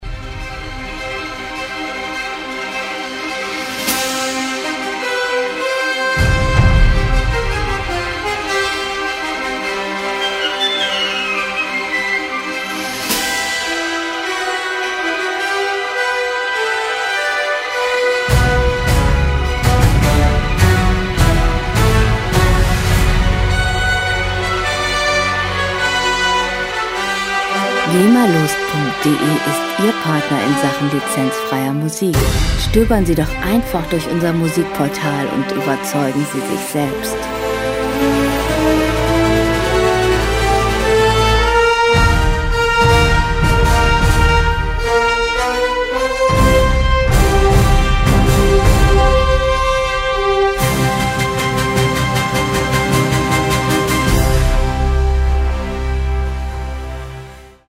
Filmmusik - Abenteuer
Musikstil: Soundtrack
Tempo: 169 bpm
Tonart: H-Moll
Charakter: tobend, gefahrvoll
Instrumentierung: Orchester